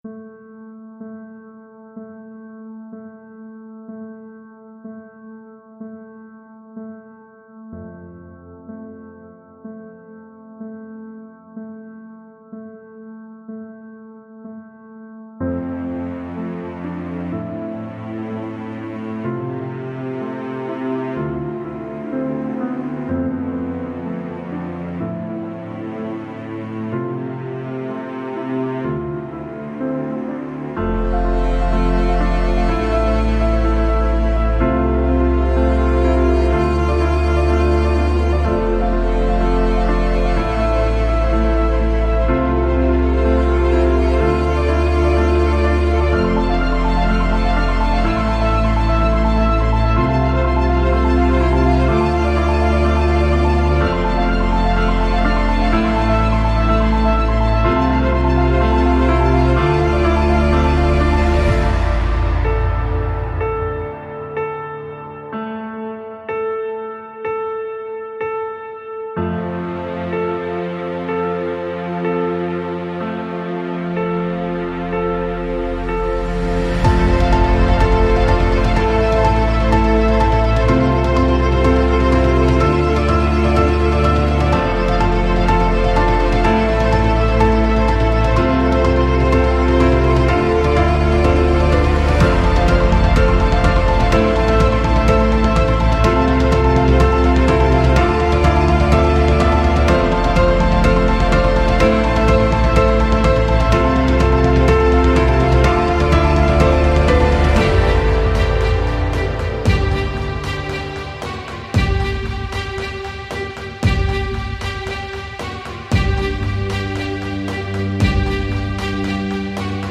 这个包再次受到史诗和奇幻电影乐谱的启发，将使您的Cinematic曲目更上一层楼，
内部有拍手，鼓，撞击声，弦，撞击，钢琴，弦，弦断音，弦延音，弓弦，低音提琴，汤姆，合唱团，
人声，独奏，双簧管，牛角，牛角，铜管，游乐设施和金属打击乐器。
-5个混音演示
-110-125 BPM